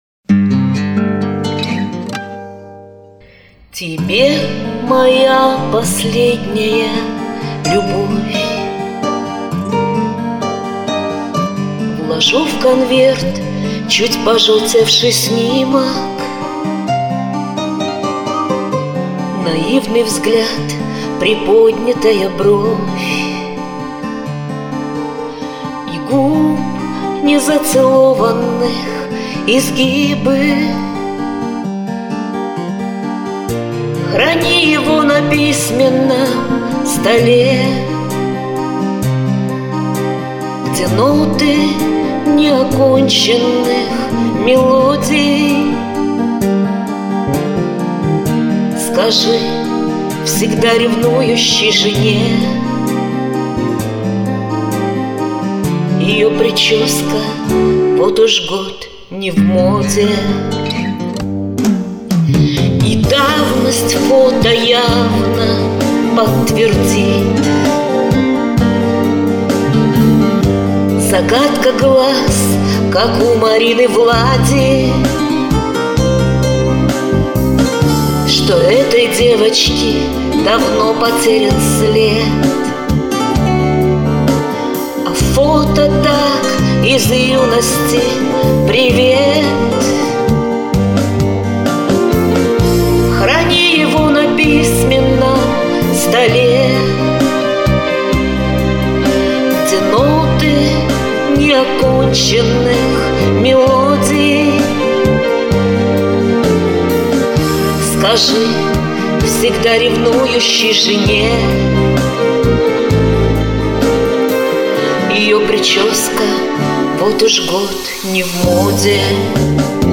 естественно голос звучит